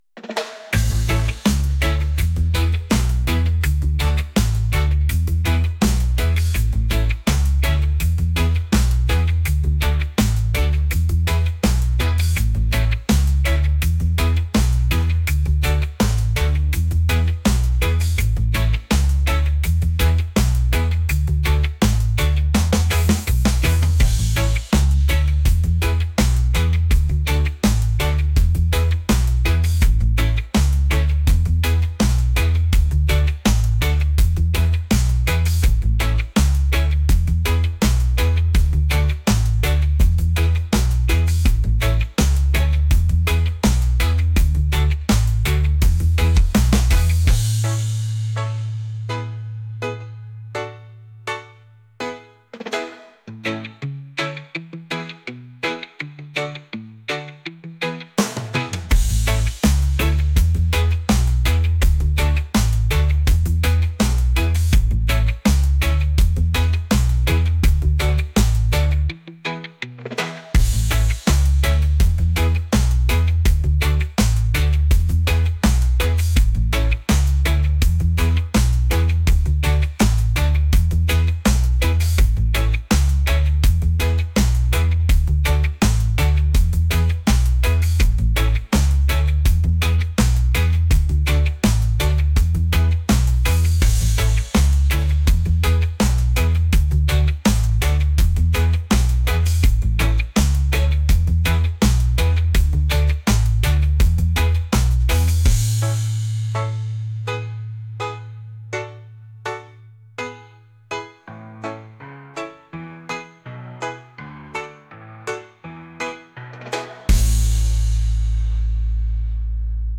reggae | pop | folk